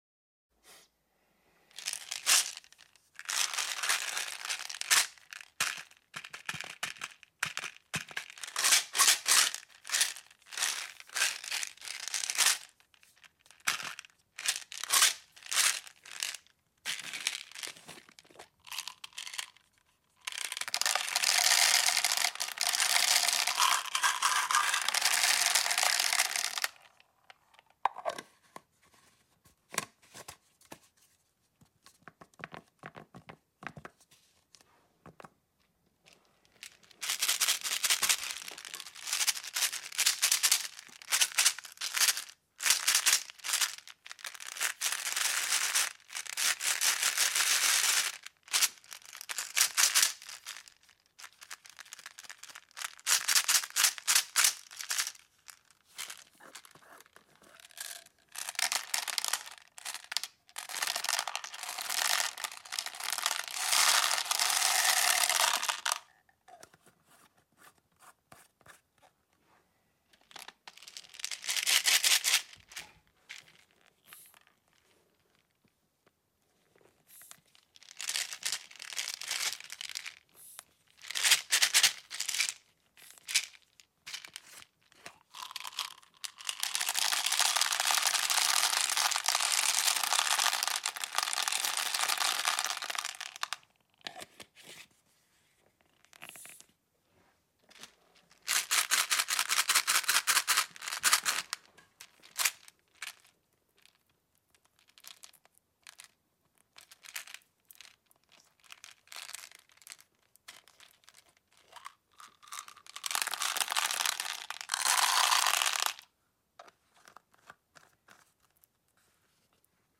Color Serenity ASMR | Flowing sound effects free download
Color Serenity ASMR | Flowing Hues for Pure Calm 🌊🌈